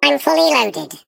Sfx_tool_spypenguin_vo_max_storage_reached_02.ogg